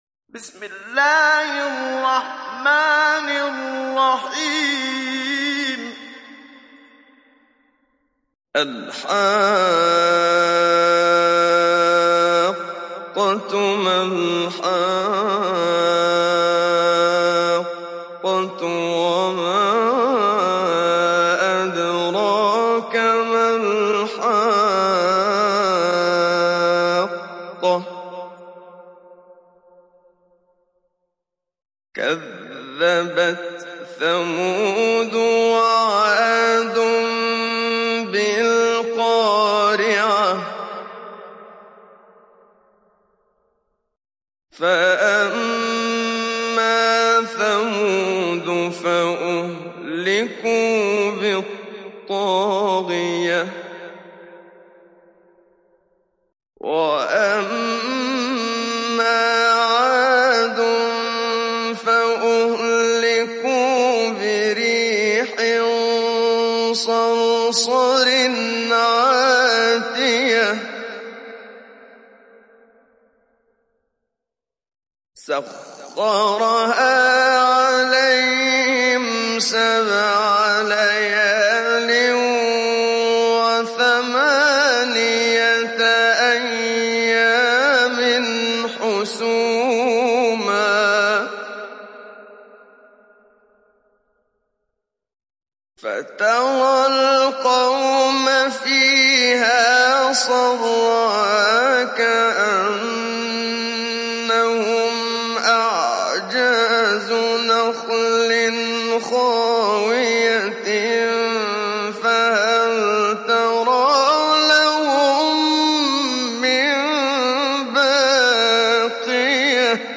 تحميل سورة الحاقة mp3 محمد صديق المنشاوي مجود (رواية حفص)
تحميل سورة الحاقة محمد صديق المنشاوي مجود